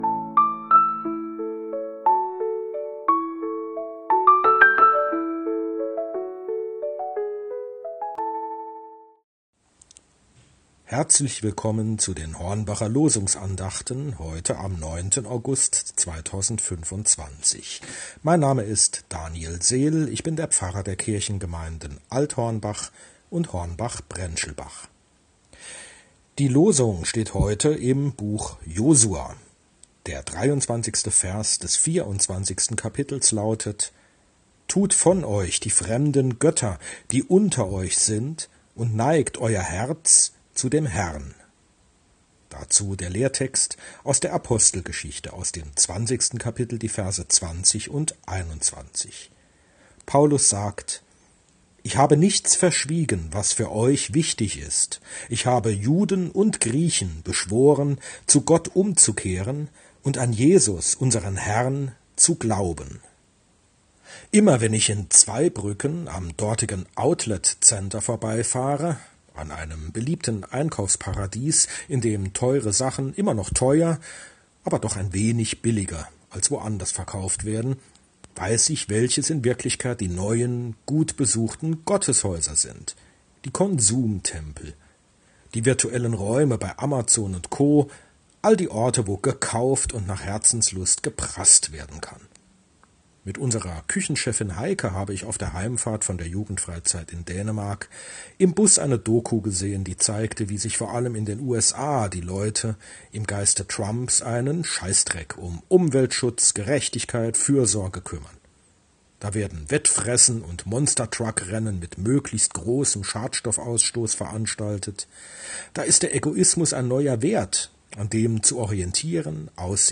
Losungsandacht für Samstag, 09.08.2025 – Prot.